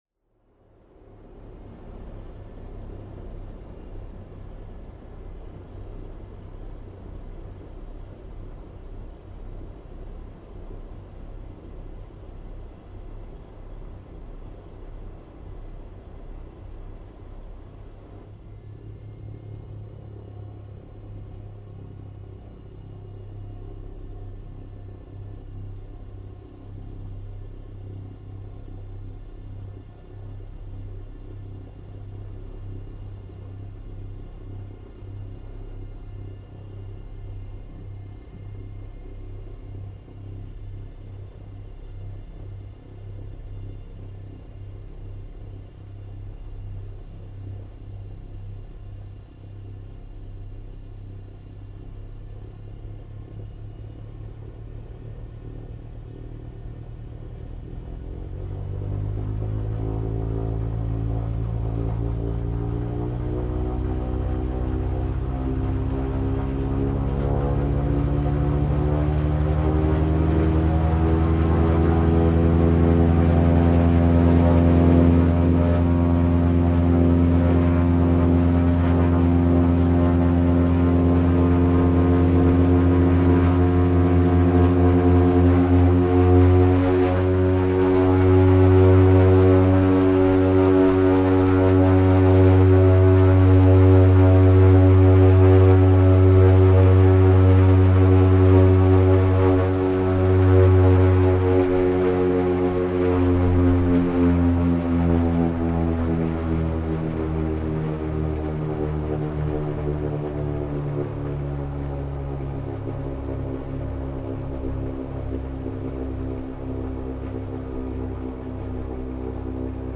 Шум дирижабля в полете над землей